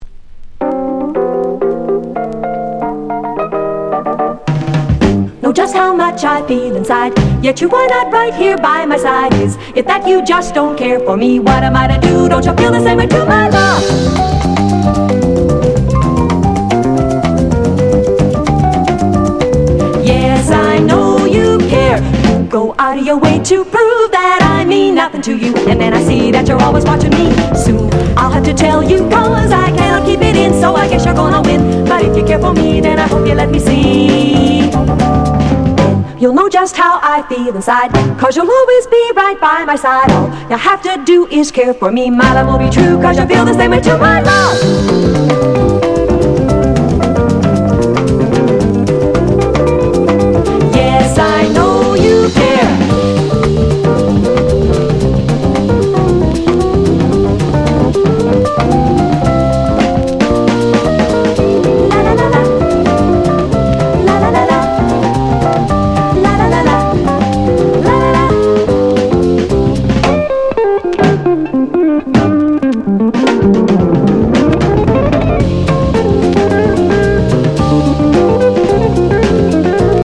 ジャジー＆ラテン・ソフトロック好盤！